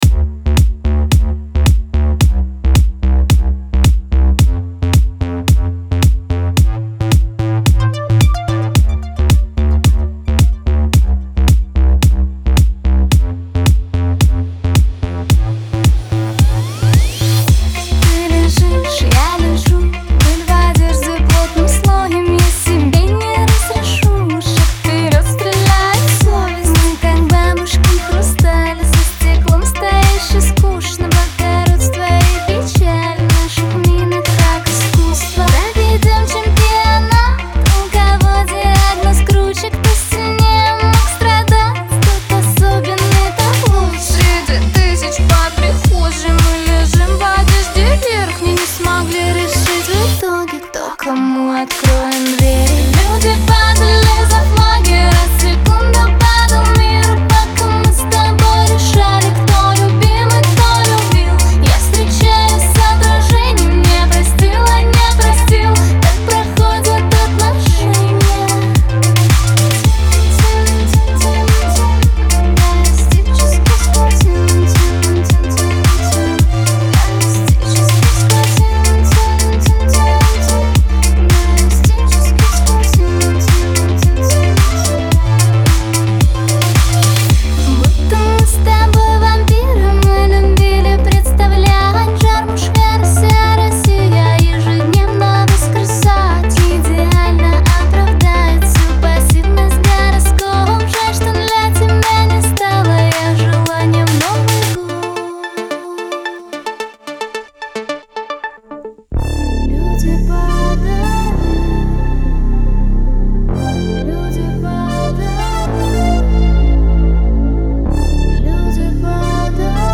эмоциональный поп-рок трек